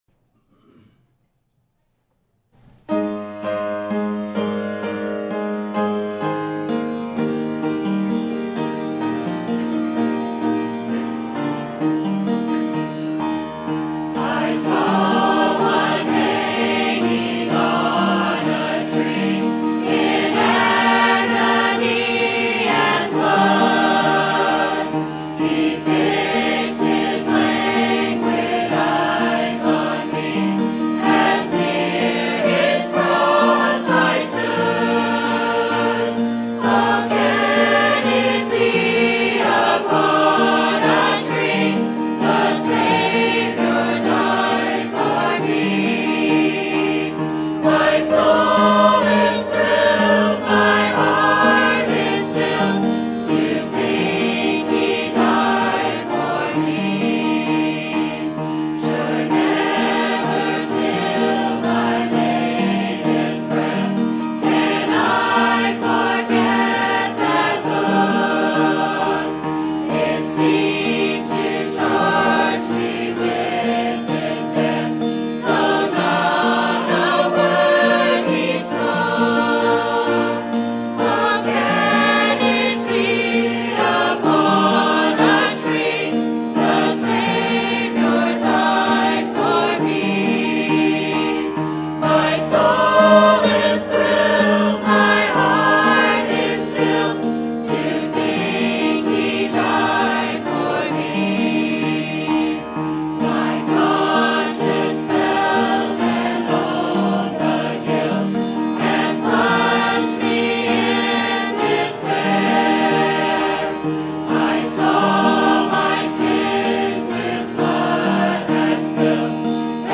Sermon Topic: Winter Revival 2015 Sermon Type: Special Sermon Audio: Sermon download: Download (16.41 MB) Sermon Tags: Luke Revival Room Jesus Christ